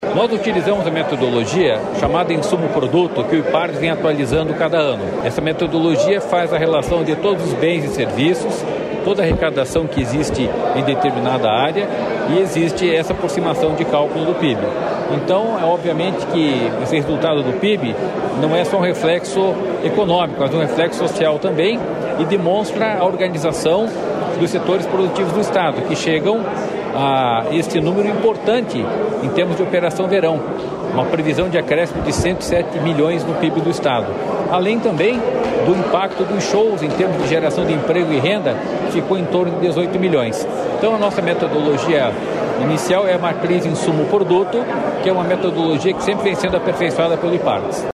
Sonora do diretor-presidente do Ipardes, Jorge Callado, sobre o balanço do Verão Maior Paraná 2023/2024